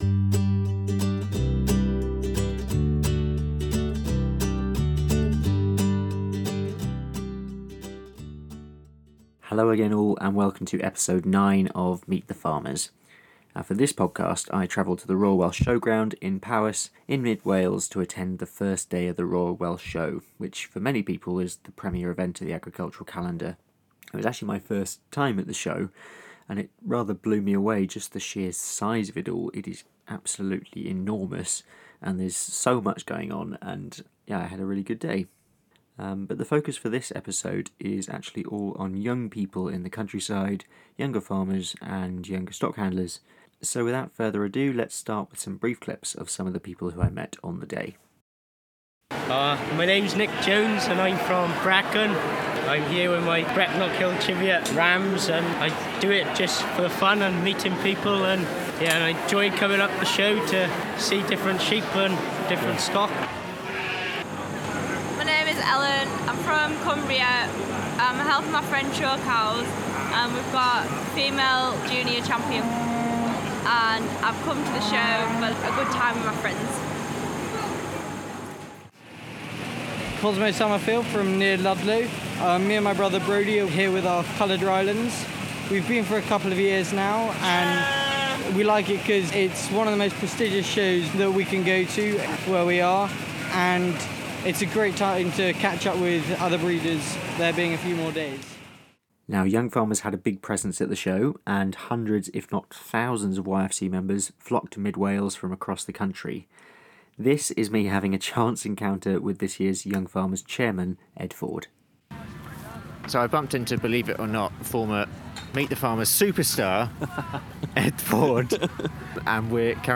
I visited the Royal Welsh Show at Builth Wells, Powys last month.